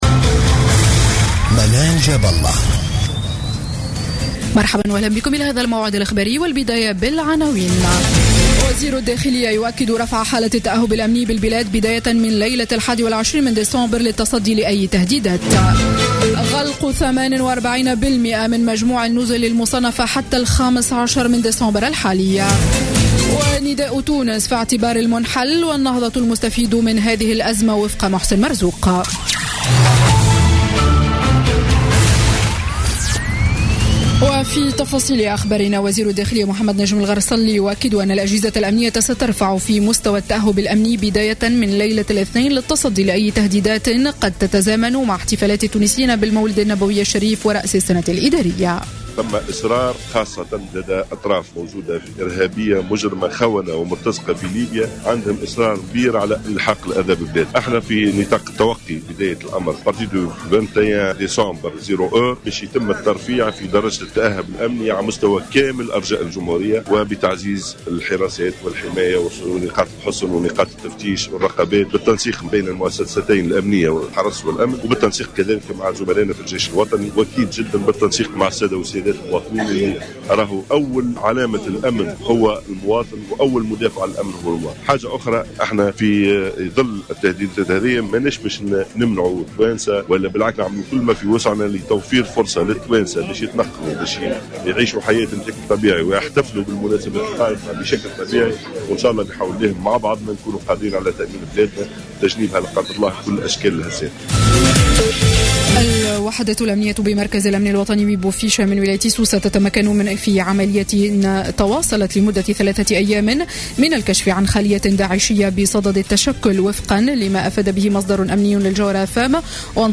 نشرة أخبار السابعة مساء ليوم الجمعة 18 ديسمبر 2015